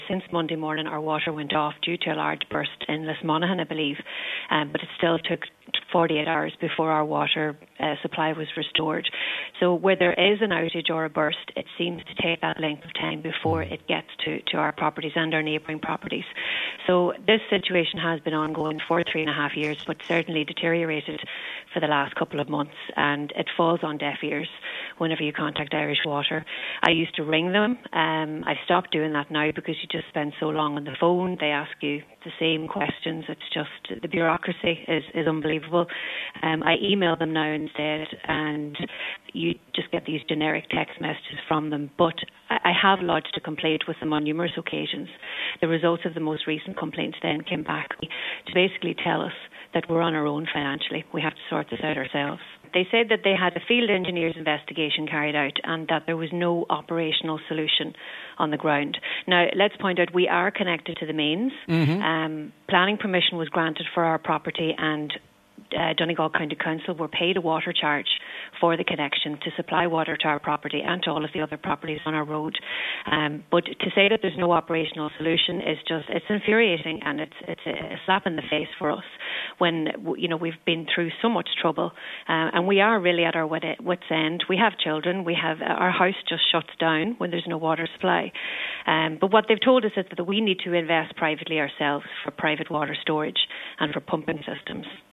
She told today’s Nine til Noon Show that they have been left ultimately with an ultimatum to invest in the water supply themselves: